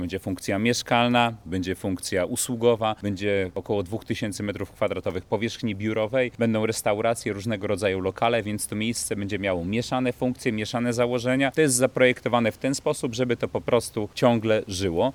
To jest zaprojektowane w ten sposób, żeby to po prostu ciągle żyło – mówi Adam Pustelnik, wiceprezydent Łodzi.